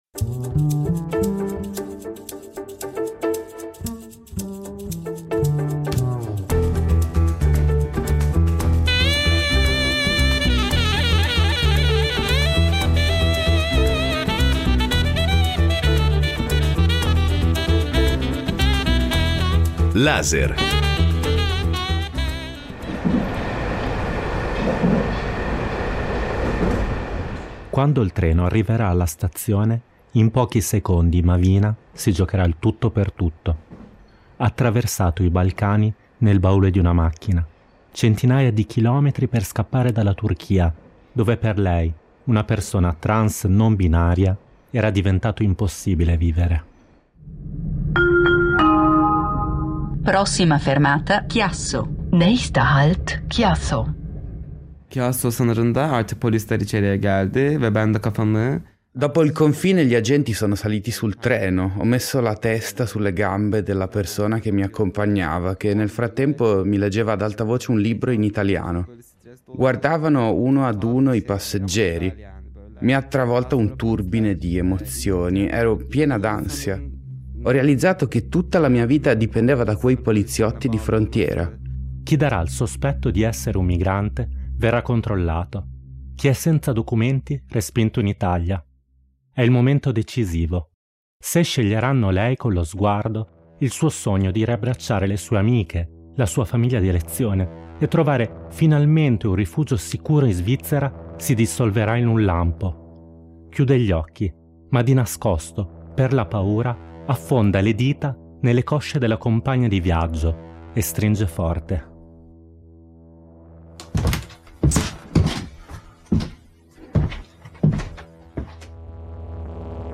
In questo audio documentario raccontano come sono arrivate in Svizzera e cosa si sono lasciate alle spalle, mentre le trame delle loro storie si intrecciano con quella di un viaggio tra Basilea, Ginevra, Delémont, Baden e tutti i luoghi dove hanno trovato rifugio e ora - tra non poche difficoltà e la paura di essere di nuovo costrette alla fuga - stanno cercando di costruirsi una nuova vita, finalmente libere di essere sé stesse.